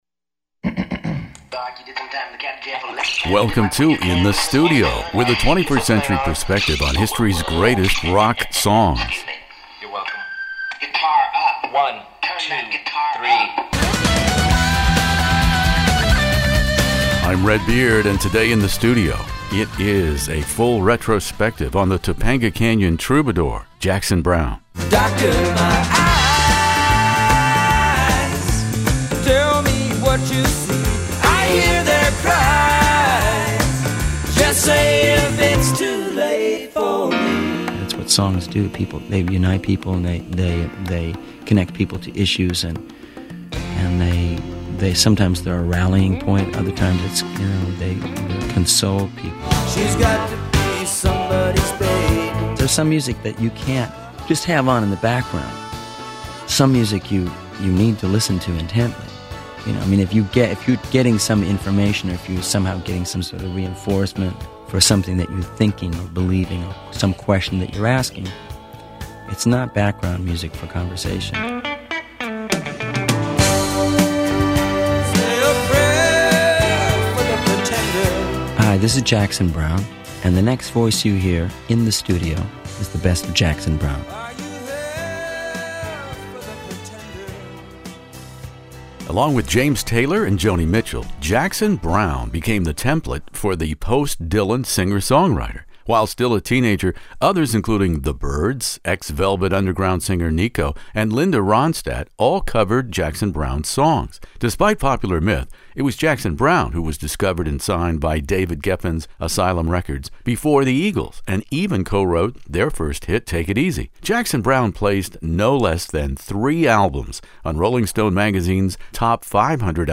Jackson Browne interview for the fiftieth anniversary of his January 1972 debut album In the Studio.